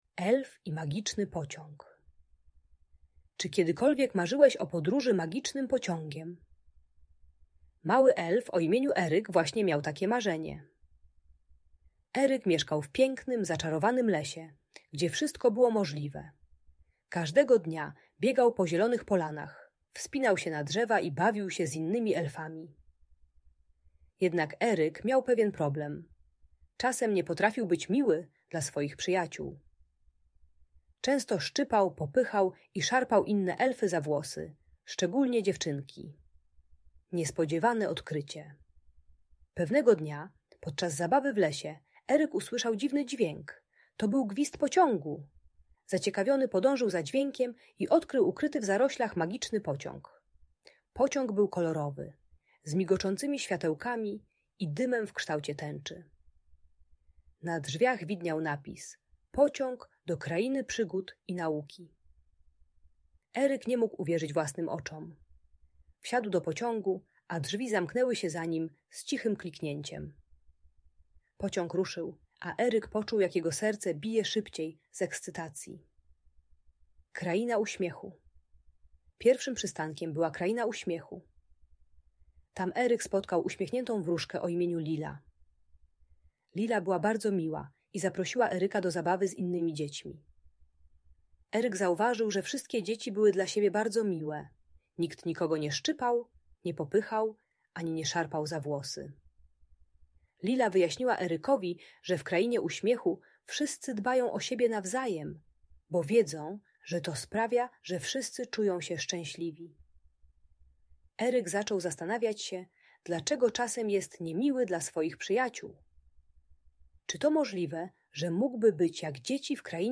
Elf i Magiczny Pociąg - Urocza historia dla dzieci - Audiobajka dla dzieci